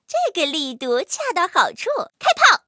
M2中坦夜战攻击语音.OGG